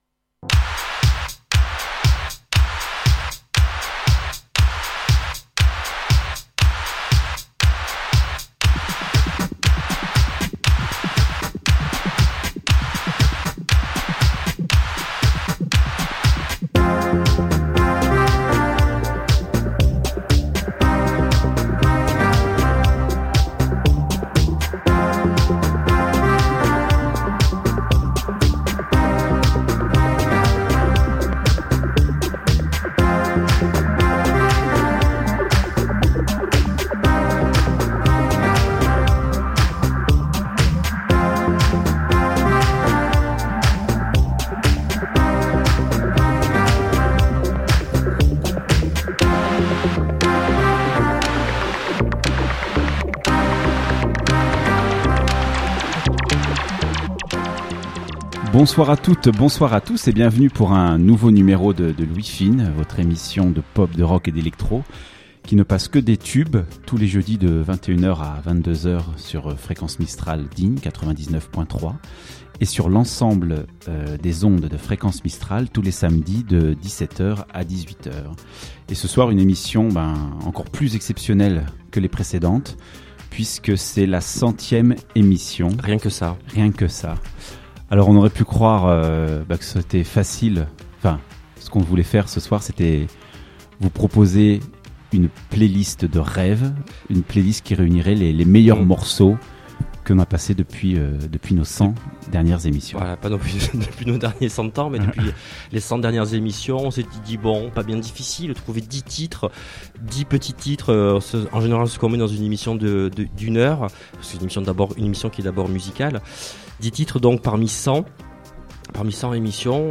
L’ouïe fine, c’est la SEULE émission de musique actuelle (pop, rock, électro) produite et diffusée sur Digne, sur les ondes de Fréquence Mistral 99.3 Mhz.
En direct les jeudis sur Fréquence Mistral Digne 99.3 Mhz de 21h00 à 22h00 et en rediffusion le samedi de 17h00 à 18h00 sur l'ensemble des ondes de FM.